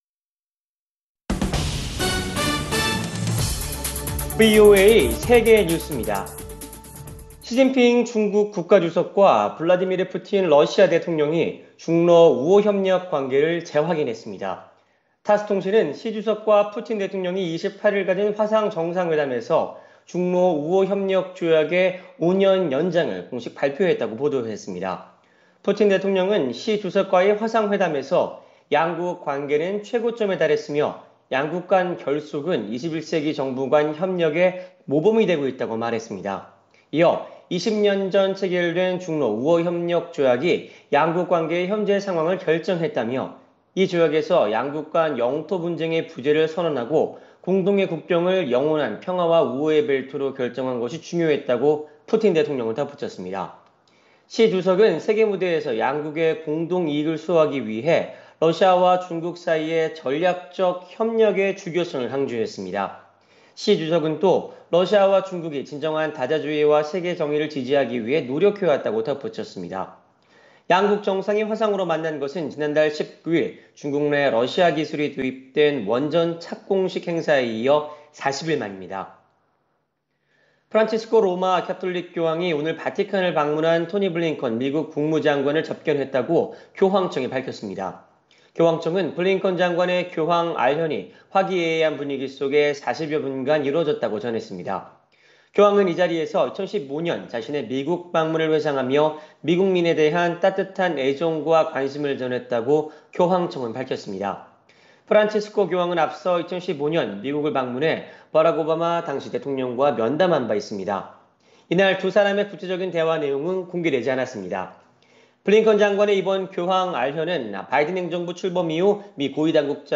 VOA 한국어 간판 뉴스 프로그램 '뉴스 투데이', 2021년 6월 28일 3부 방송입니다. 북한 정권이 미-북 대화 가능성을 일축하고 있지만, 바이든 행정부는 계속 북한과 외교적 대화를 시도해야 한다고 미국 의회의 일부 의원들이 권고했습니다. 북한 정권이 돈세탁 등 국제 불법 금융 거래와 관련해 고위험 국가 명단인 블랙리스트에 그대로 남아 있다고 국제자금세탁방지기구가 밝혔습니다.